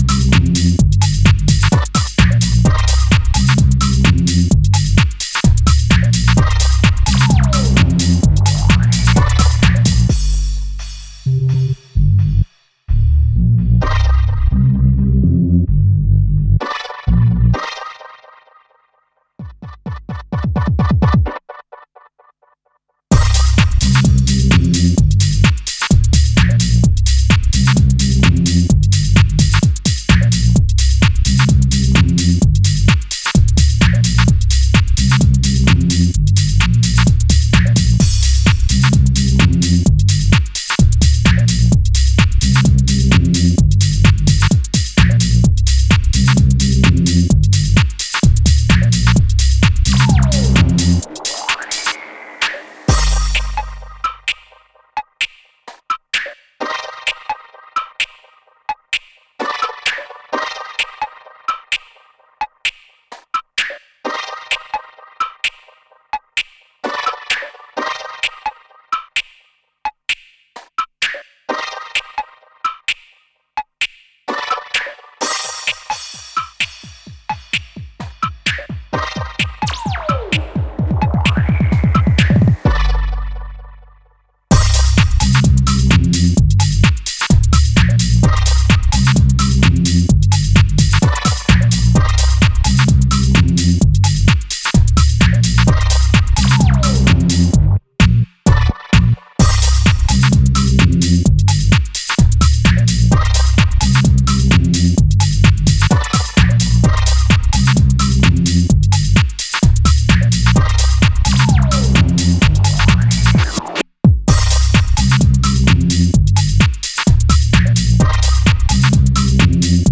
emotionally charged house narratives.